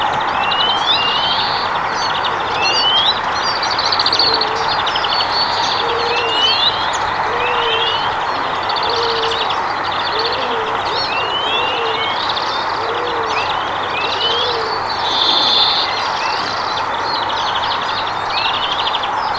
sepelkyyhkykoiraan lähikosinta ääntelyä, 1
Ääni tietääkseni muistuttaa hieman isoturturikyyhkyn laulua.
tuo_uuu-u_on_sepelkyyhkykoiraan_kosinta-aantelya_kun_se_pomppii_kosien_naaraansa_perassa.mp3